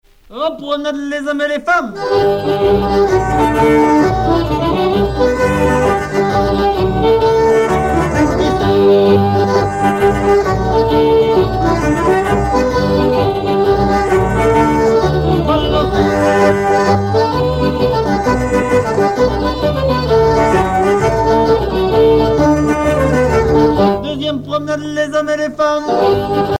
danse : quadrille : grand galop
Pièce musicale éditée